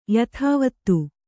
♪ yathāvattu